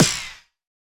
hitBaxter_Far.wav